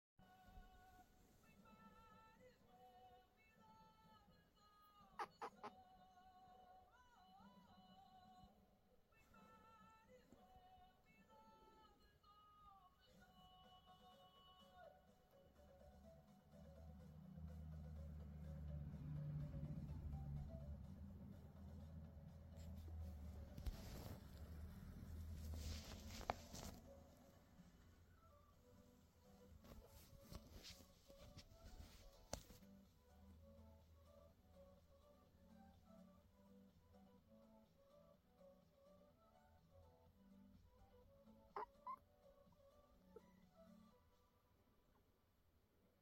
sound up!! for the best lil squeaks while peans wakes up 🥰